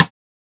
Snare (Alien).wav